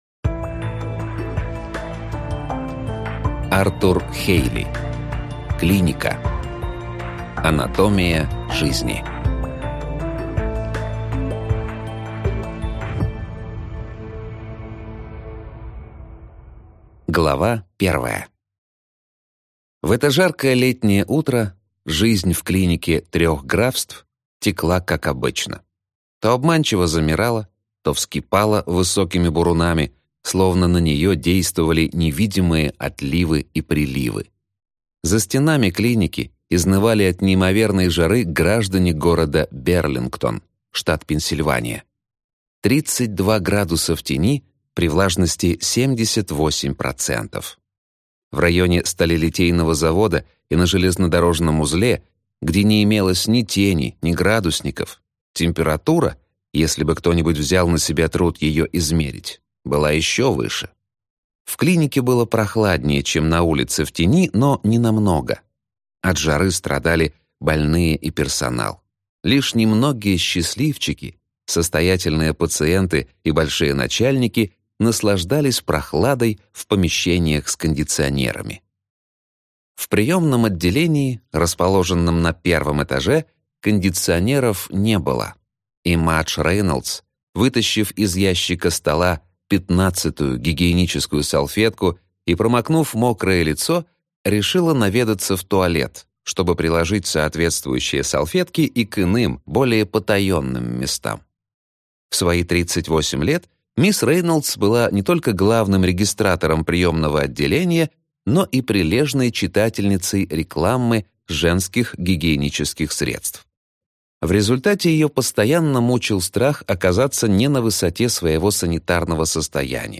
Аудиокнига Клиника: анатомия жизни | Библиотека аудиокниг